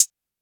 PV_HiHat ( OZ ).wav